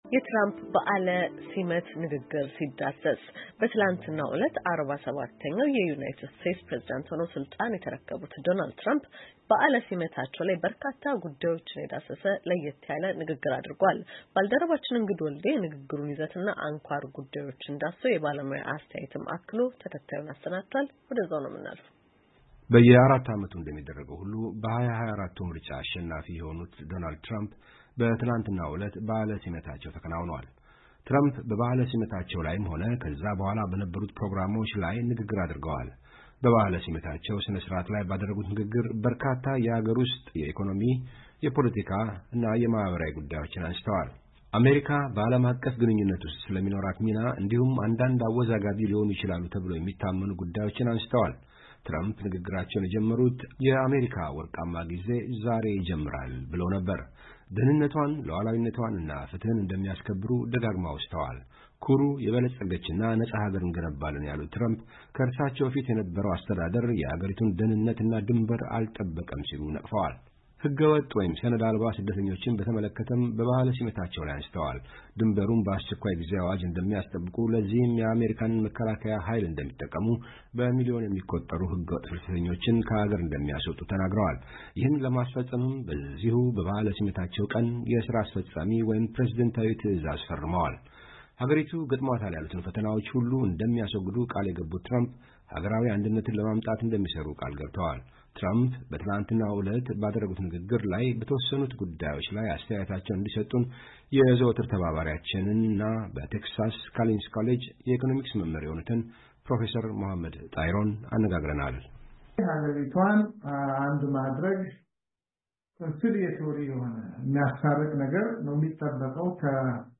ትረምፕ በትላንትናው ዕለት ባደረጉት ንግግር በተወሰኑት ጉዳዮች ላይ የባለሞያ አስተያየት ጠይቀናል። ዝርዝሩን ከተያያዘው ፋይል ይከታተሉ።